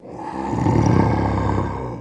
Human Vocals
Monster Growl Dinosaurs and Relic Human Voice Pitched